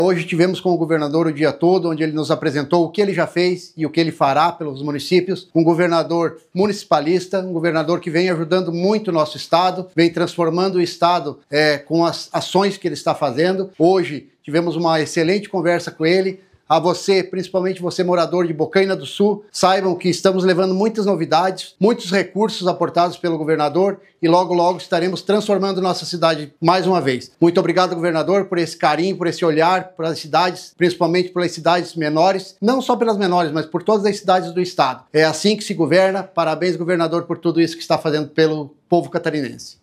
SONORA – SC Levada a Sério: prefeito de Bocaina do Sul celebra investimentos para o município
Após a conversa individual com o governador Jorginho Mello, o prefeito de Bocaina do Sul, João Eduardo Della Justina, celebrou investimentos para o município:
SECOM-Sonora-SC-Levada-a-Serio-Prefeito-Bocaina-do-Sul.mp3